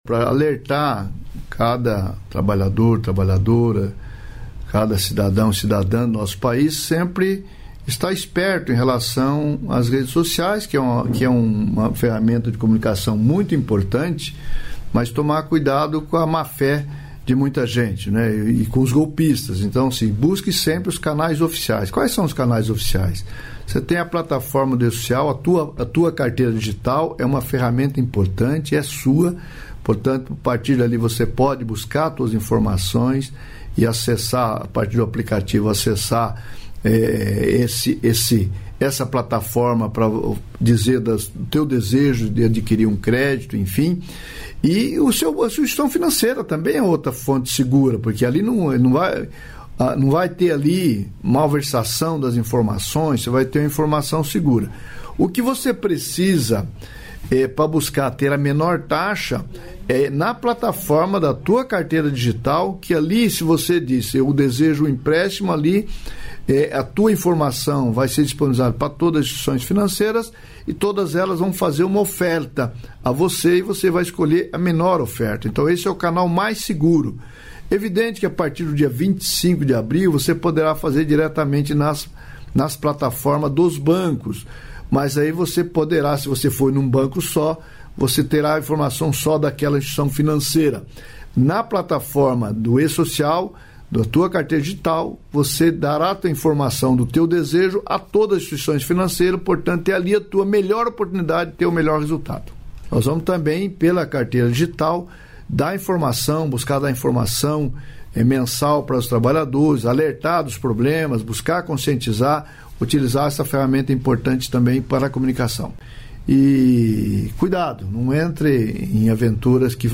Trecho da participação do ministro do Trabalho e Emprego, Luiz Marinho, no programa "Bom Dia, Ministro" desta quinta-feira (13), nos estúdios da EBC, em Brasília.